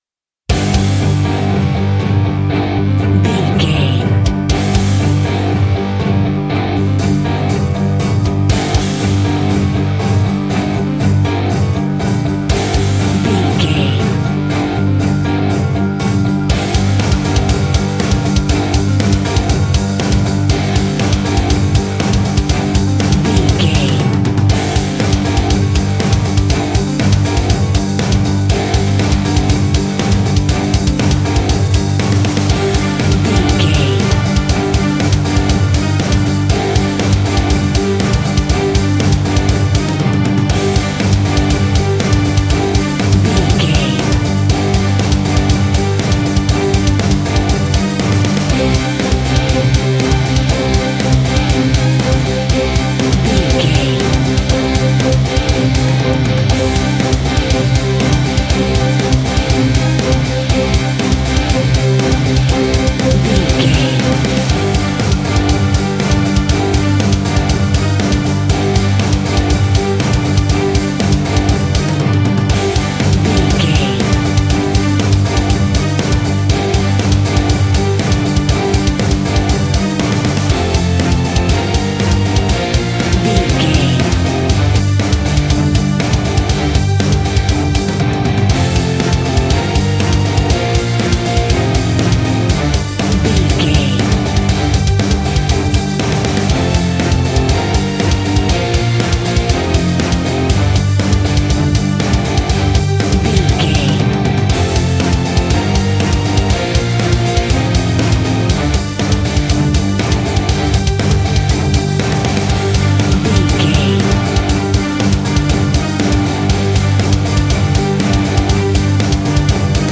A great piece of royalty free music
Epic / Action
Fast paced
Aeolian/Minor
hard rock
instrumentals
Heavy Metal Guitars
Metal Drums
Heavy Bass Guitars